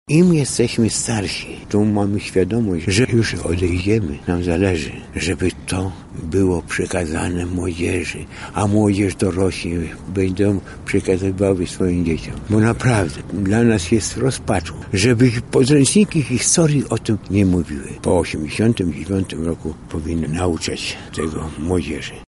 Pod tym hasłem obchodziliśmy dziś 79-tą rocznicę masowych deportacji Polaków na Syberię.